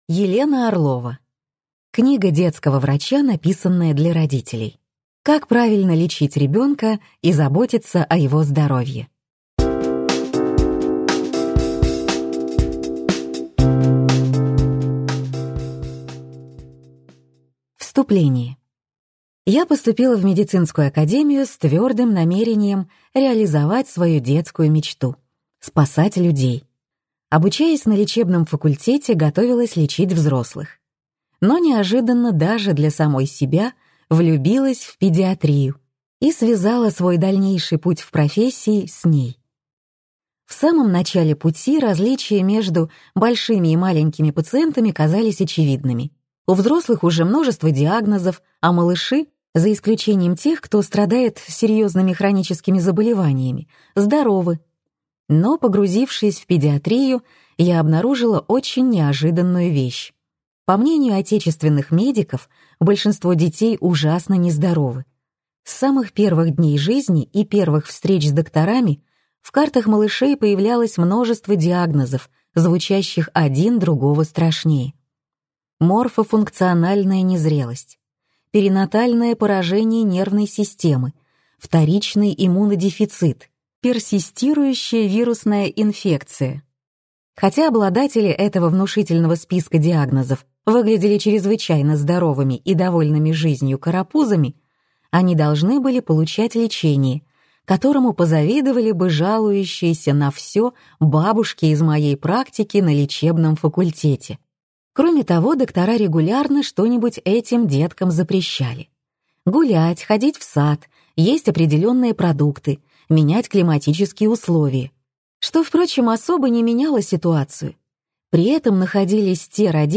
Аудиокнига Книга детского врача, написанная для родителей. Как правильно лечить ребенка и заботиться о его здоровье | Библиотека аудиокниг